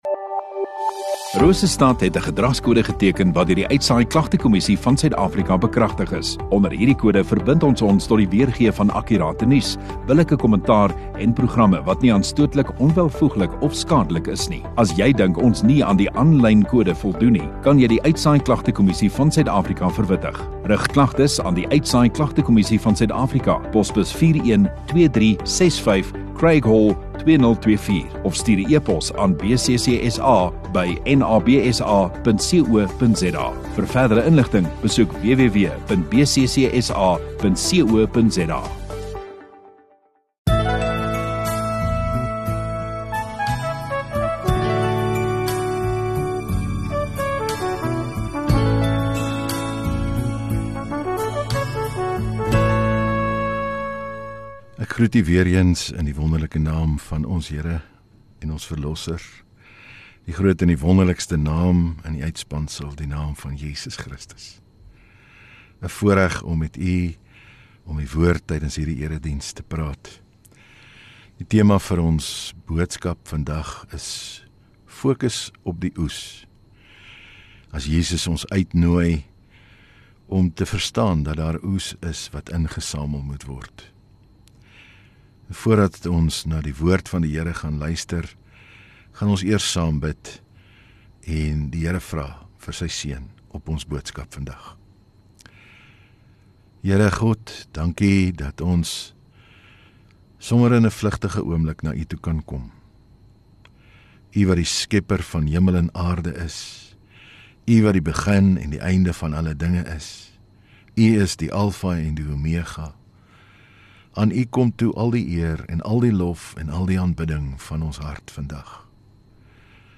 13 Apr Sondagaand Erediens